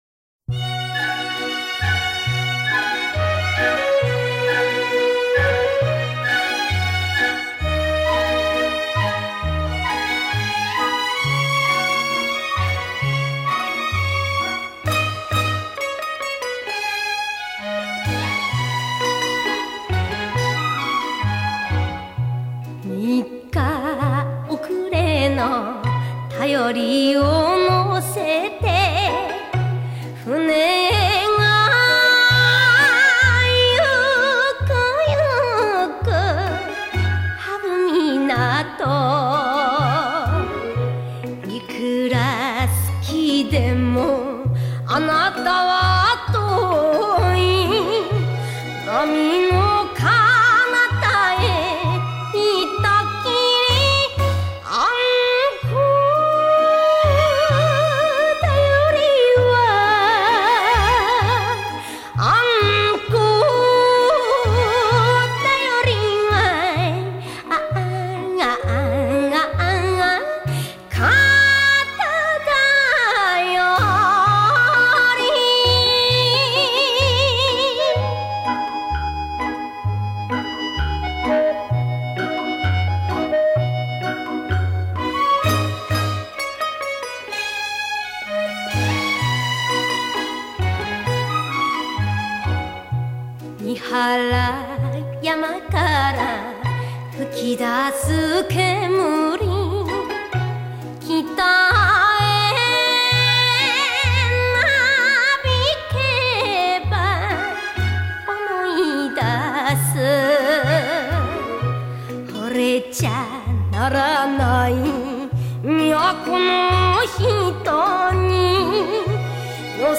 앤카